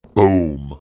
big_boom.ogg